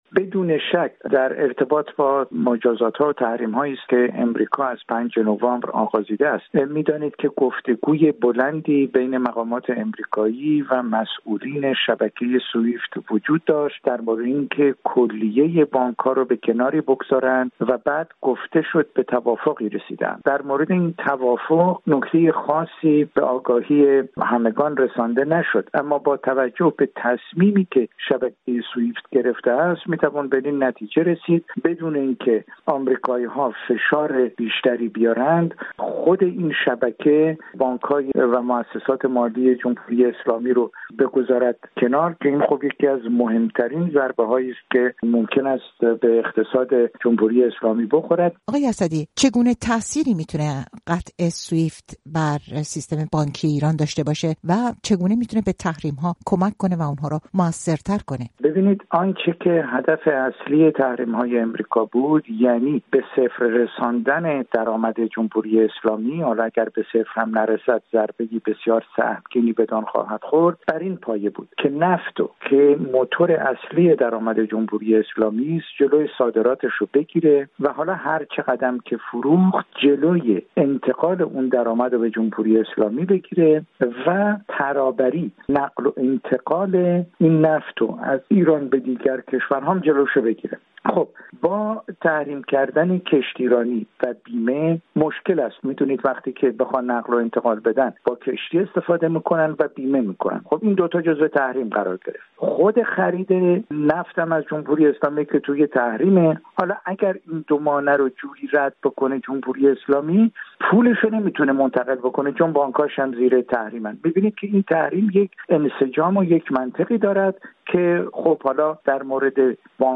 اقتصاددان در فرانسه